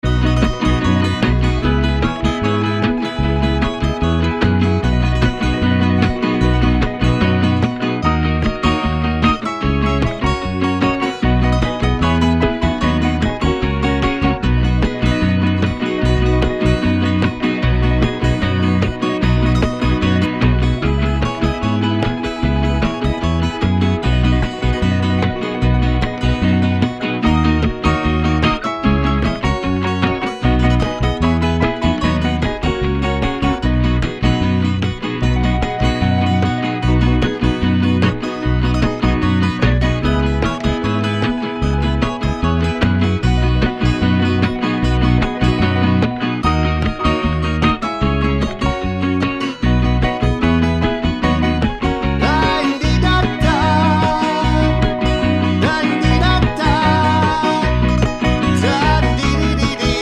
no harmonica or Backing Vocals Pop (1970s) 3:24 Buy £1.50